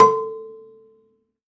53p-pno14-C3.wav